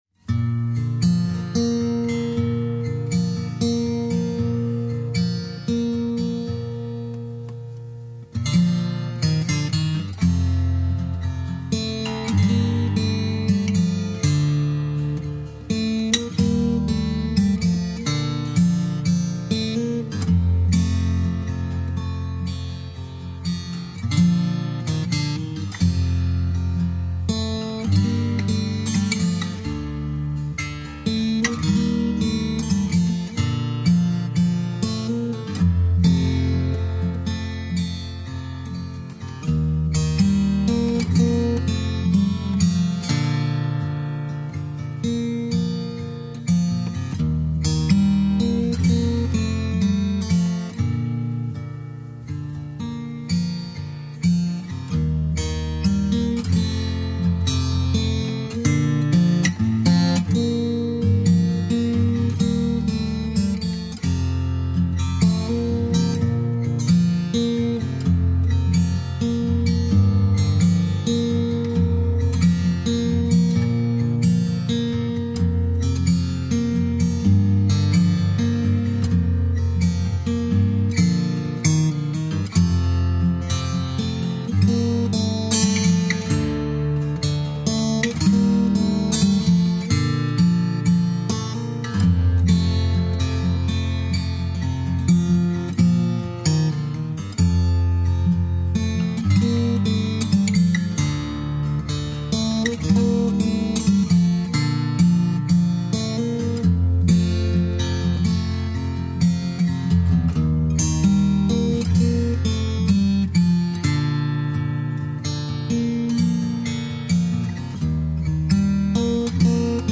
【新世纪吉他】
音乐风格：New Age / Classical